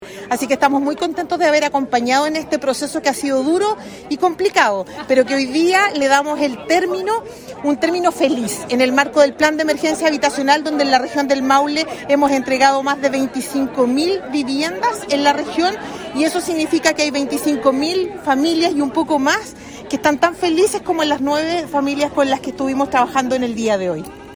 SERVIU_Sueno-Hermoso_directora-SERVIU.mp3